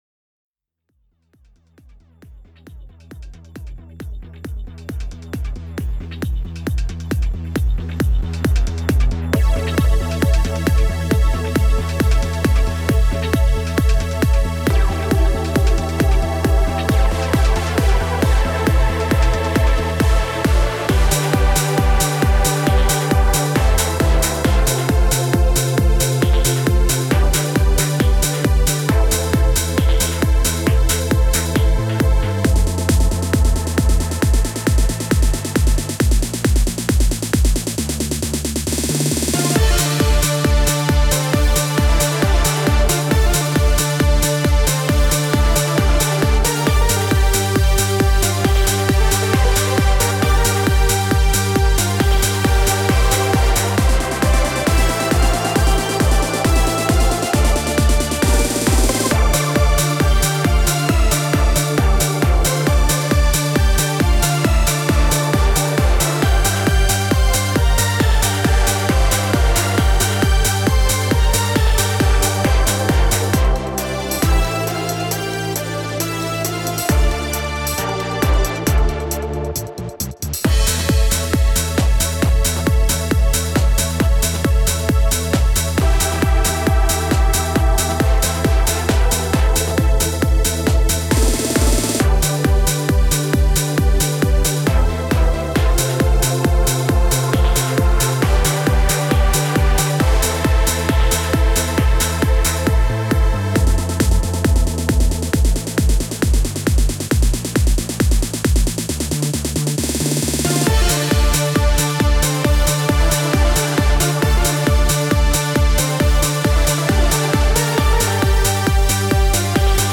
ορχηστρικές συνθέσεις